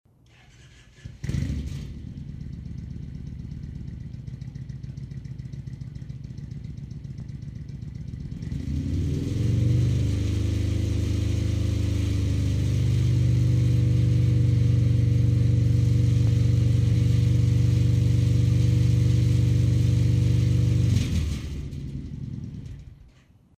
Everything was recorded at exactly the same level.
Baffle only, no packing
64 db idle 84 db 3000 rpm